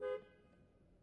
camry horn
描述：Recorded in 2012 with a Nikon camera.
标签： camry carhorn horn honk toyota car